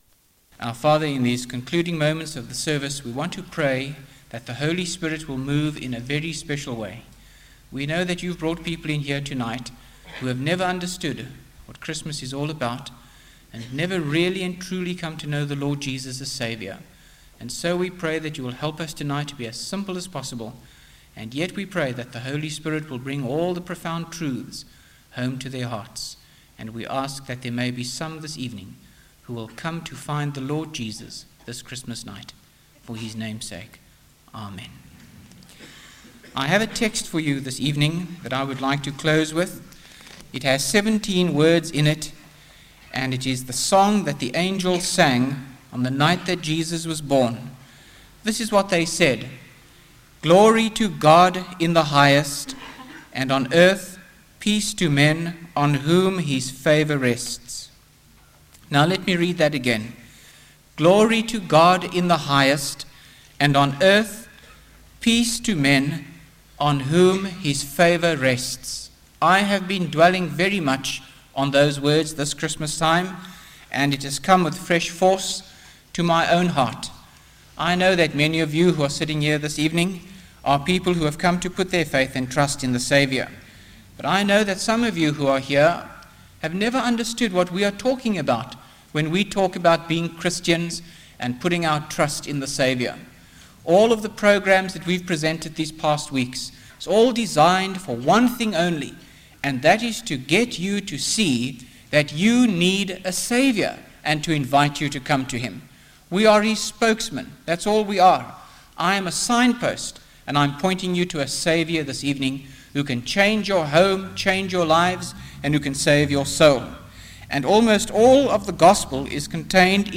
by Frank Retief | Jan 27, 2025 | Frank's Sermons (St James) | 0 comments